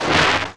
TEAR SNR.wav